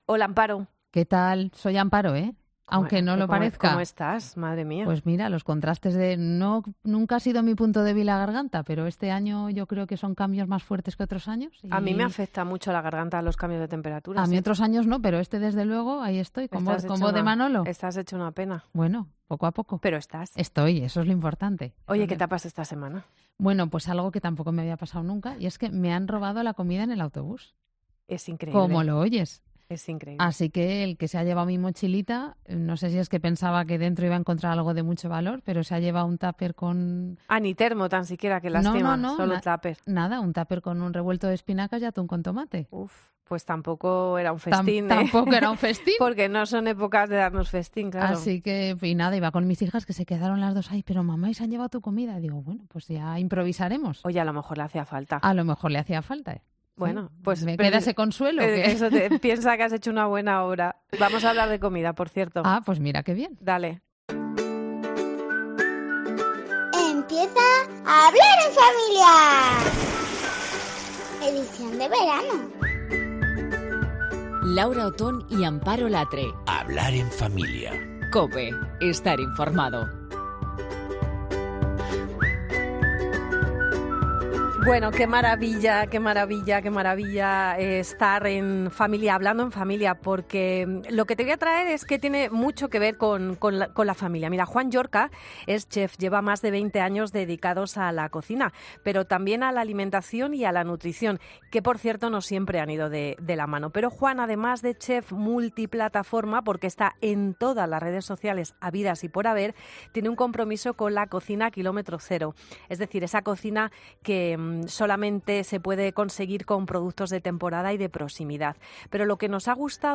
La charla con él ha estado genial, y te recomiendo que no te la pierdas porque además de comentar esas recetas nos da consejos para que empiecen a comer todos mejor.